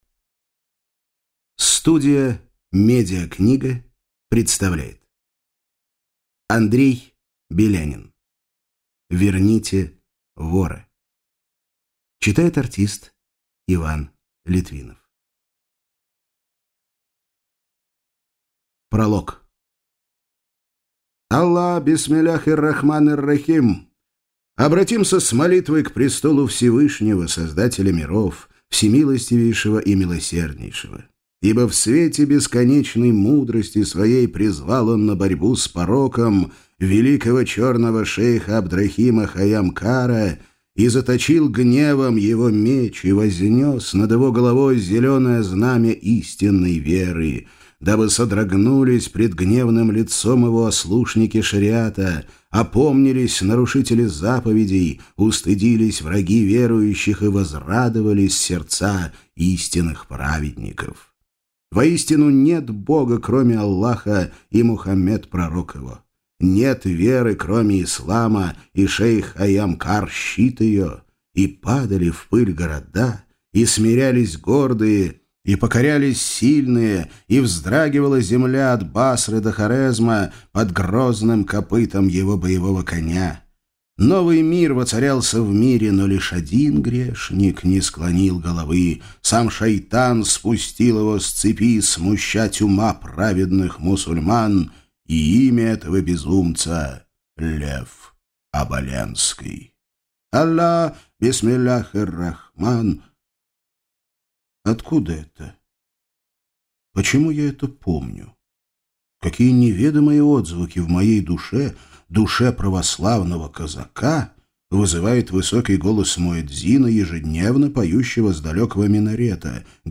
Aудиокнига Верните вора!
Прослушать и бесплатно скачать фрагмент аудиокниги